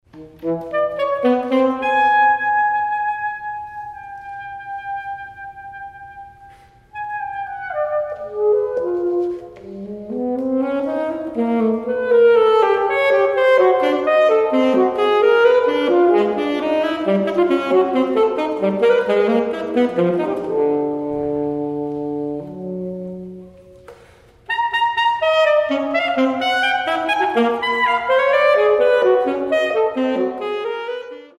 Saxophone solo